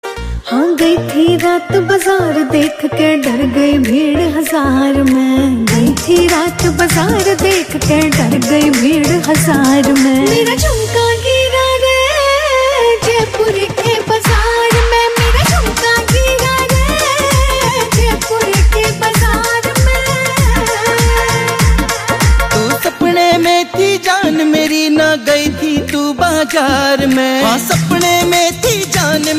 Rajasthani songs
• Simple and Lofi sound
• Crisp and clear sound